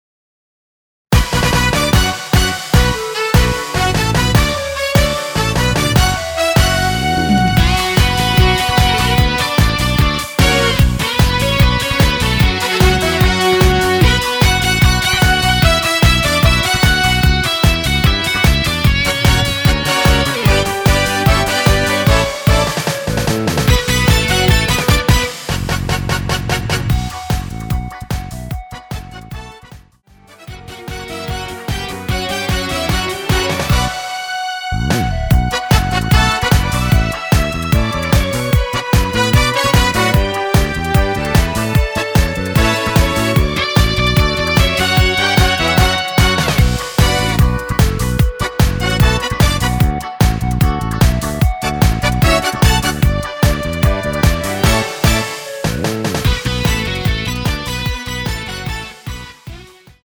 원키 멜로디 포함된 MR입니다.
Bm
앞부분30초, 뒷부분30초씩 편집해서 올려 드리고 있습니다.
(멜로디 MR)은 가이드 멜로디가 포함된 MR 입니다.